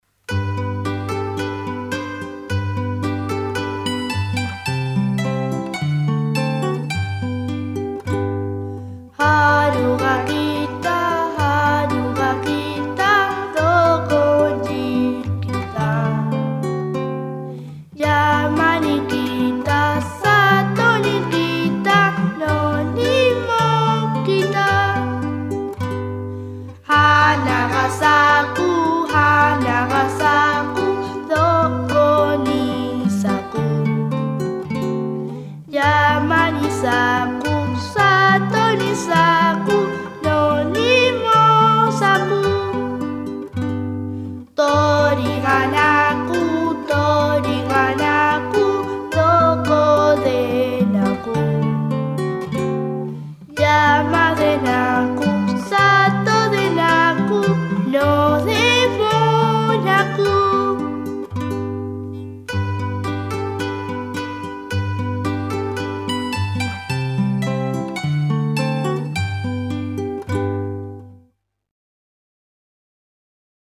canción japonesa tradicional